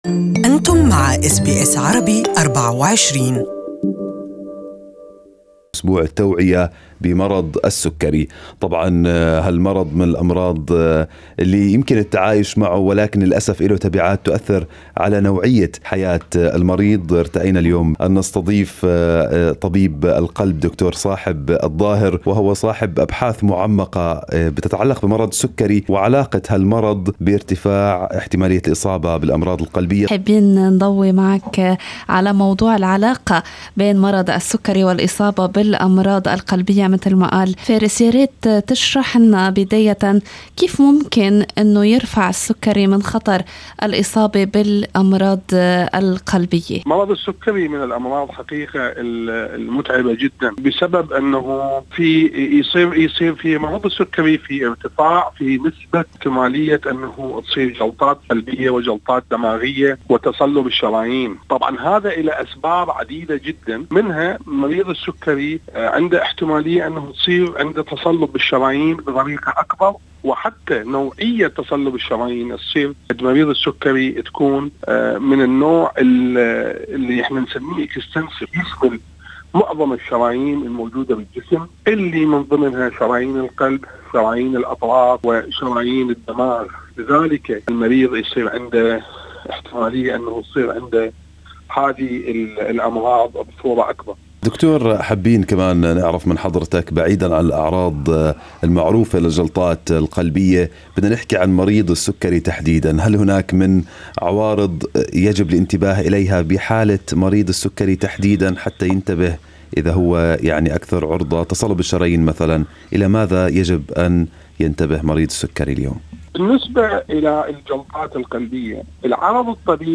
Good Morning Australia interviewed Cardiologist